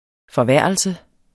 Udtale [ fʌˈvæˀɐ̯ʌlsə ]